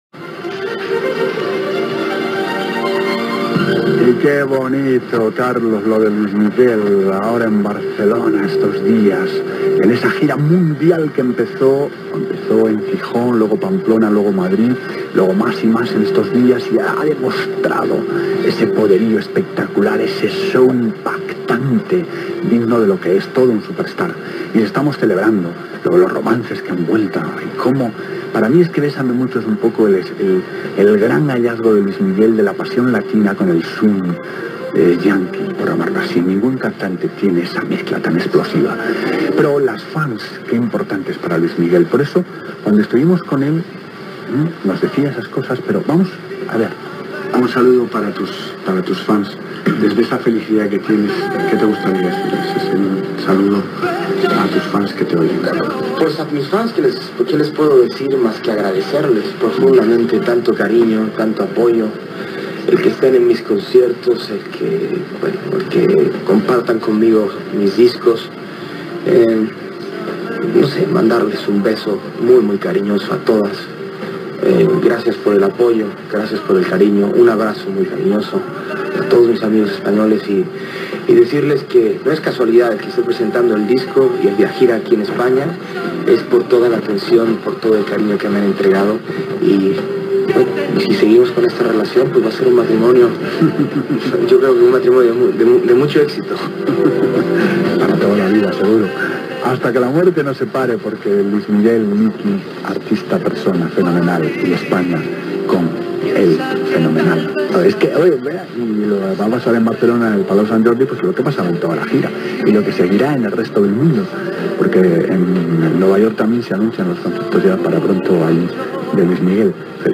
Comentari sobre el cantant Luis Miguel i paraules seves quan estava de gira a Barcelona
Musical